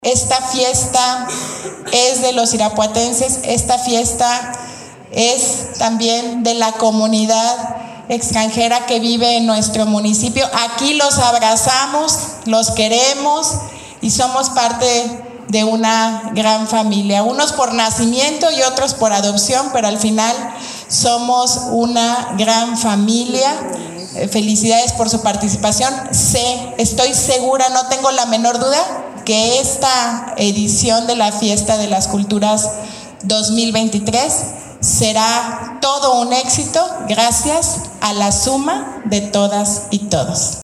AudioBoletines
Lorena Alfaro, presidenta municipal